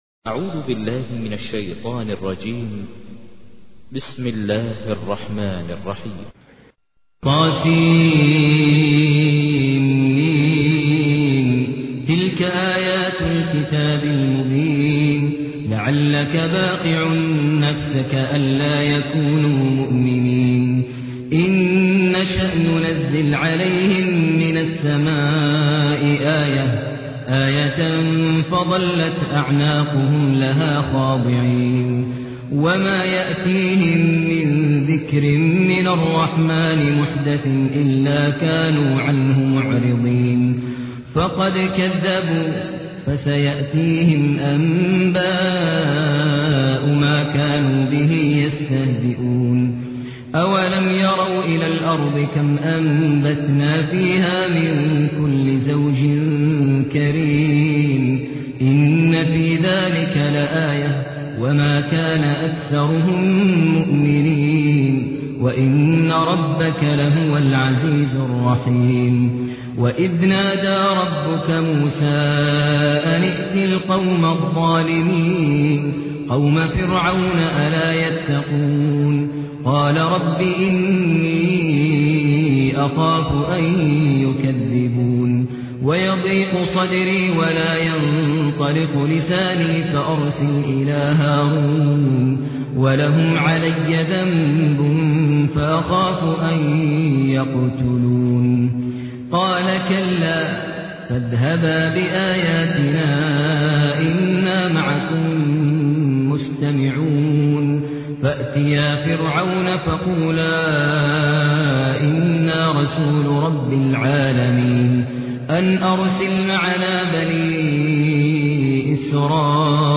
تحميل : 26. سورة الشعراء / القارئ ماهر المعيقلي / القرآن الكريم / موقع يا حسين